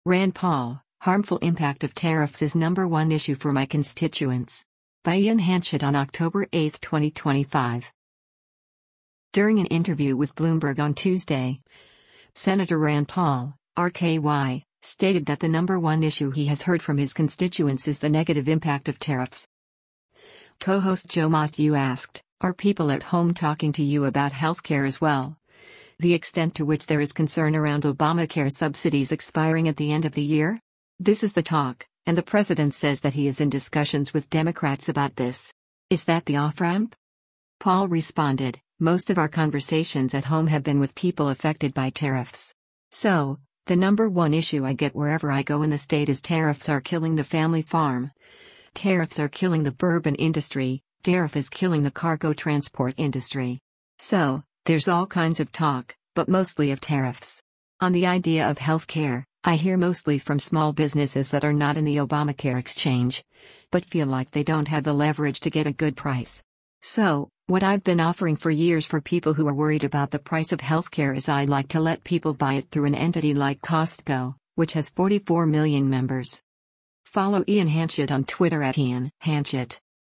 During an interview with Bloomberg on Tuesday, Sen. Rand Paul (R-KY) stated that the number one issue he has heard from his constituents is the negative impact of tariffs.